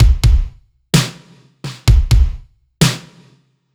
Index of /musicradar/french-house-chillout-samples/128bpm/Beats
FHC_BeatC_128-02_KickSnare.wav